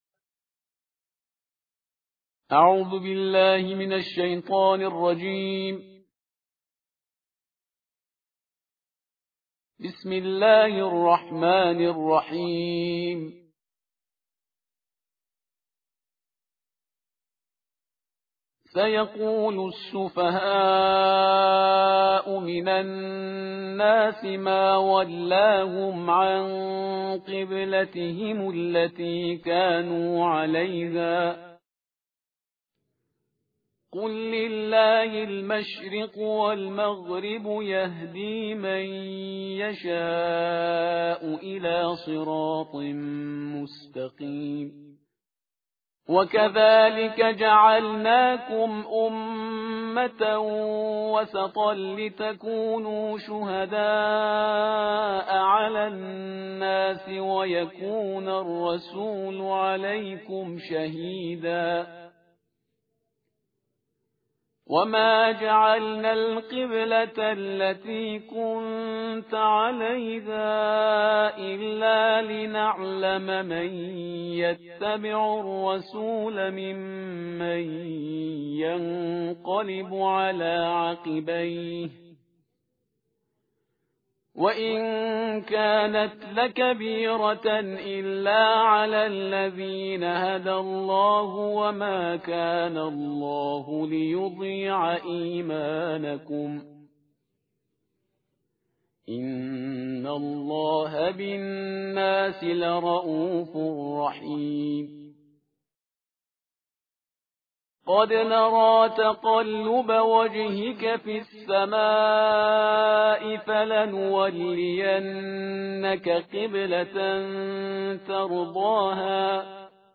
ترتیل جزء دوم قرآن کریم/استاد شهریار پرهیزگار